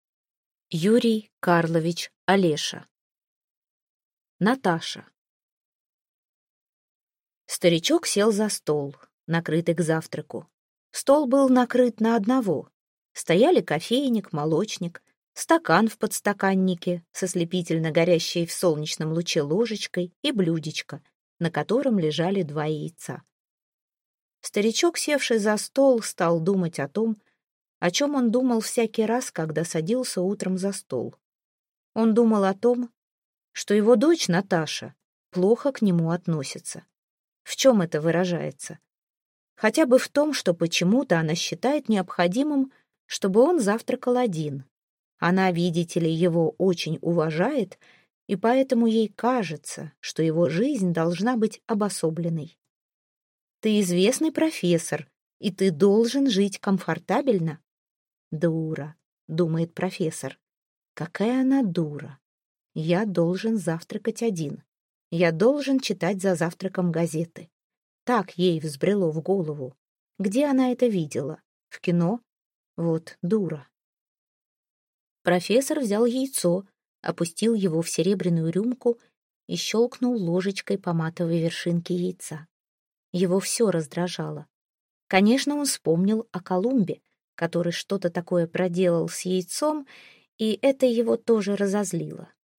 Аудиокнига Наташа | Библиотека аудиокниг